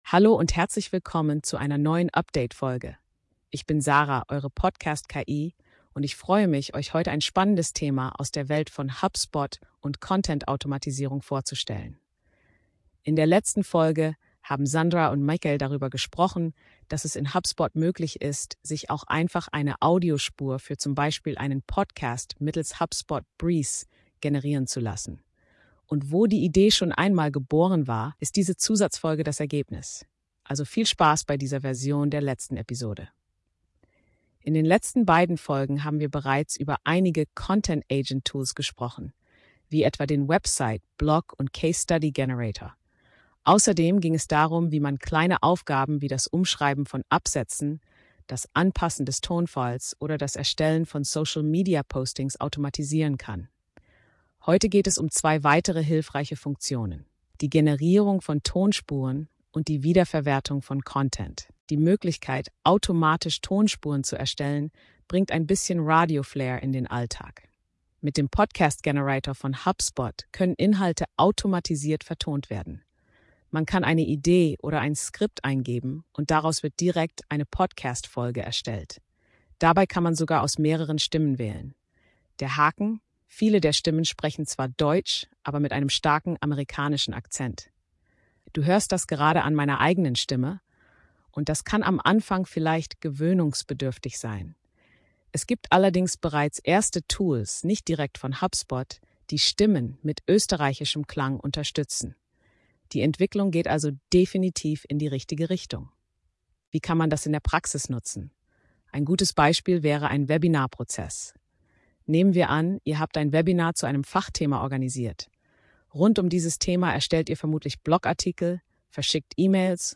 HubSpot Breeze Generated Podcast (Experiment) ~ So funktioniert HubSpot - Ein Podcast von brandREACH Podcast